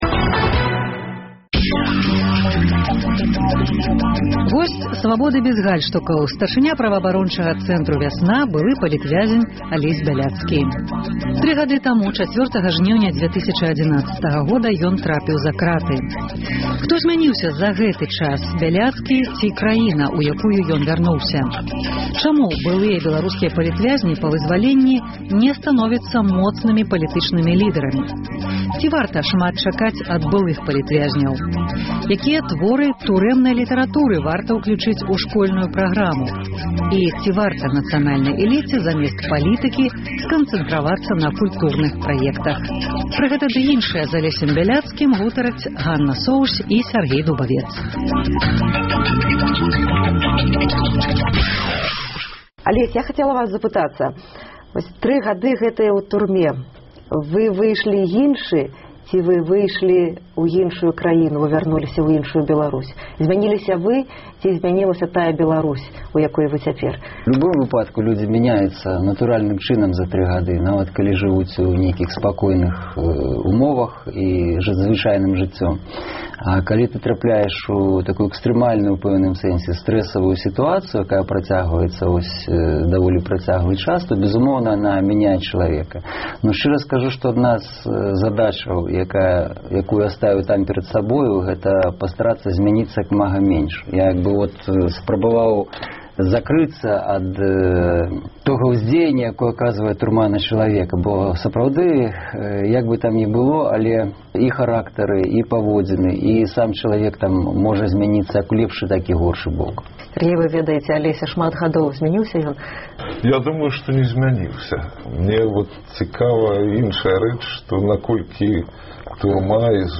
Госьць «Свабоды бяз гальштукаў» — старшыня праваабарончага цэнтру «Вясна», былы палітвязень, літаратуразнаўца, пісьменьнік, намінант на Нобэлеўскую прэмію міра 2012 і 2013 году Алесь Бяляцкі. Чаму былыя беларускія палітвязьні па вызваленьні не становяцца моцнымі палітычнымі лідэрамі? Якія творы турэмнай літаратуры варта ўключыць у школьную праграму?